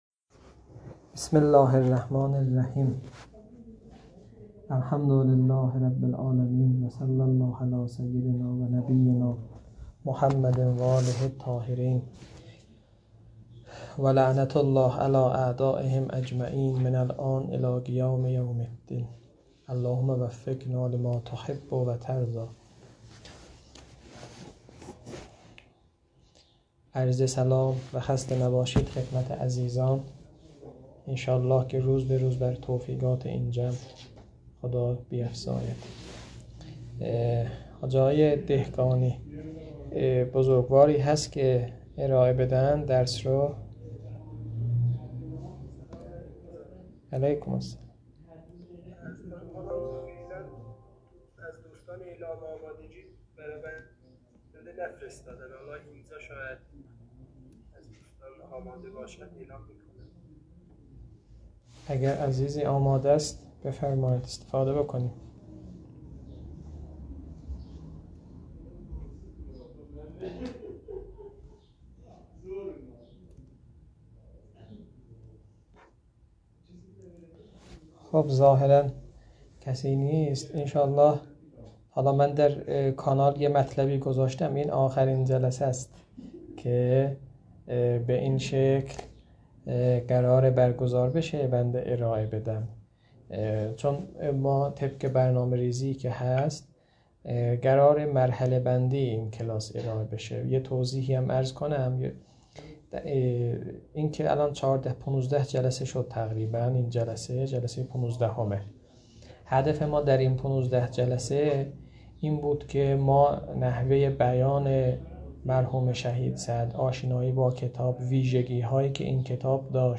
در این بخش، فایل های مربوط به تدریس كتاب حلقه ثانیه متعلق به شهید صدر رحمه الله